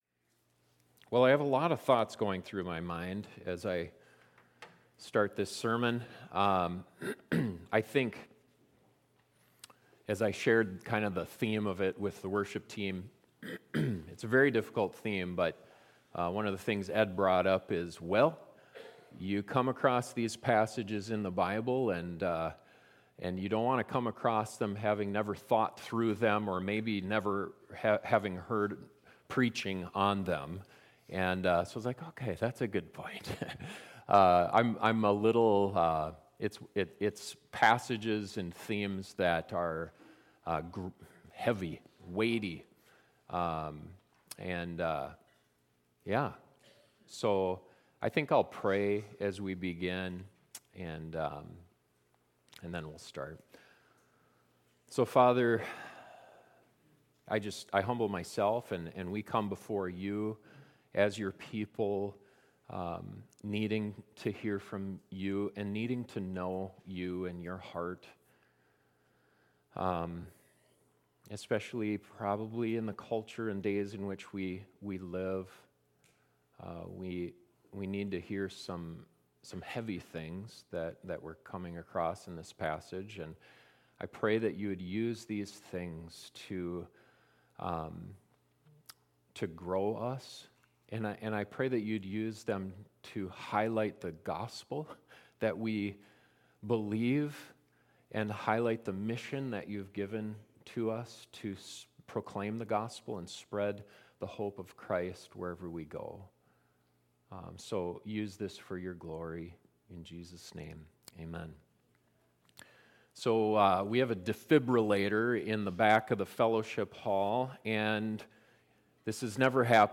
In this closing sermon on the book of Numbers we look at some of the hardest teaching in all of Scripture – God’s command to exterminate an entire group of people. It’s teaching that we need to wrestle with in order to understand God rightly, live repentant lives, and continue his mission here on earth.